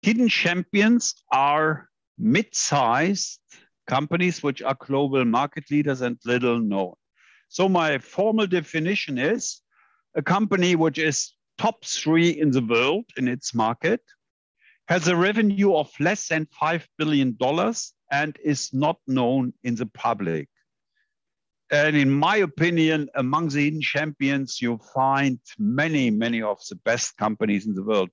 an in-depth conversation on how to understand profit in the US.